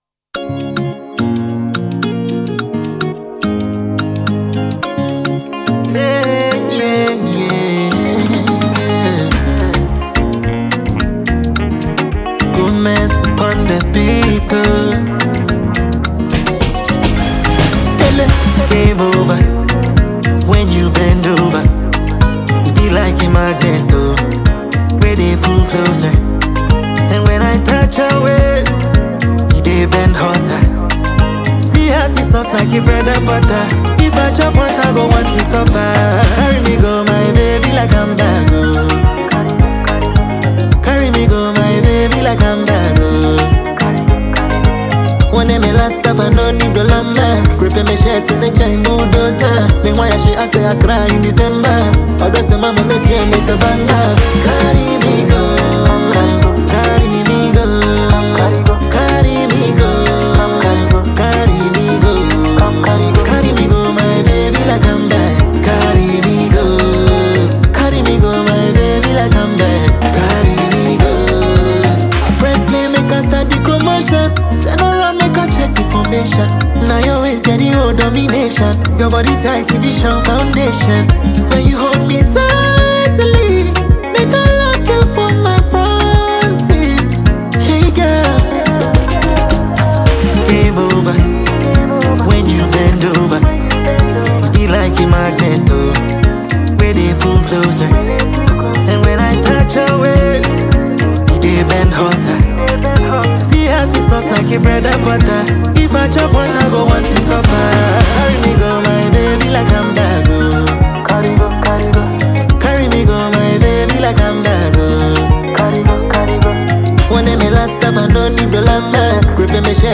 a creative Ghanaian singer
the multi-talented Ghanaian singer.